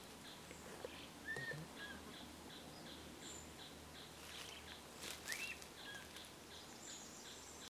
Tapaculo-ferreirinho (Scytalopus pachecoi)
Fase da vida: Adulto
Localidade ou área protegida: Parque Provincial Urugua-í
Condição: Selvagem
Certeza: Gravado Vocal
churrin-plomizo-1.mp3